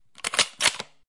卡宾枪
描述：在一个树木繁茂的半岩区的射击场上，用M1卡宾枪连续打了几枪。
标签： 枪支 枪击 M-1 步枪
声道立体声